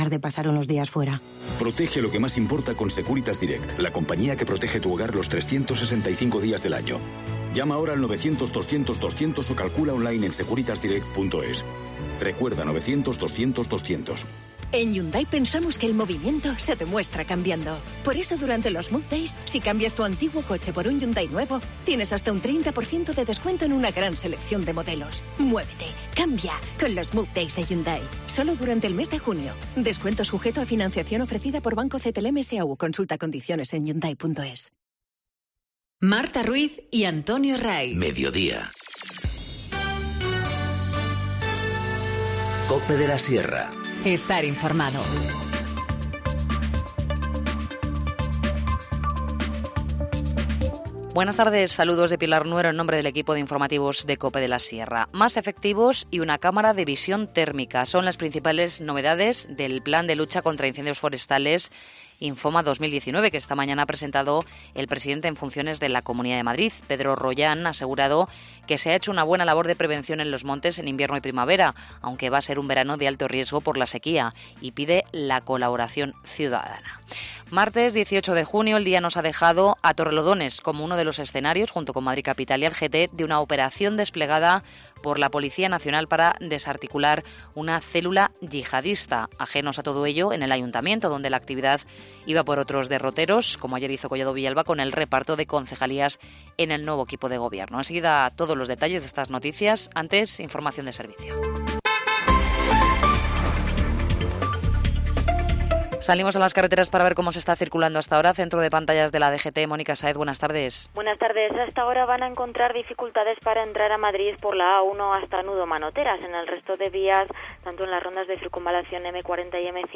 Informativo Mediodía 18 junio 14:20h